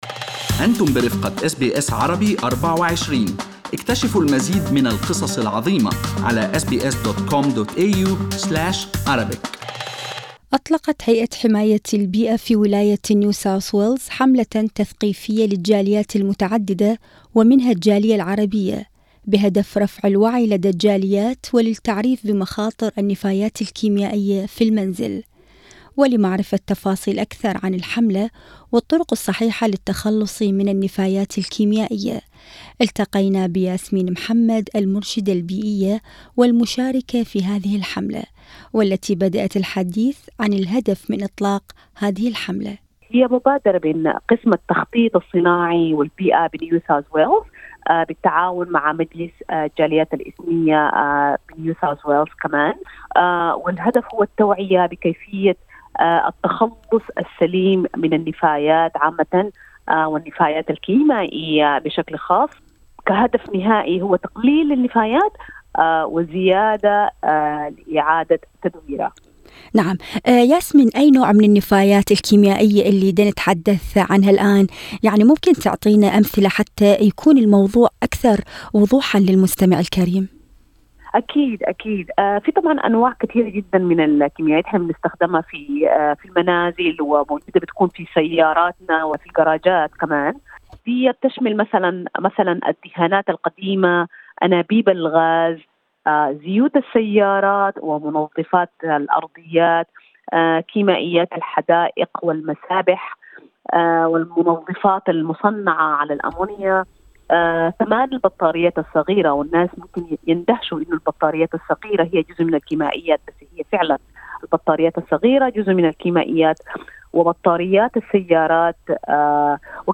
لقاءِ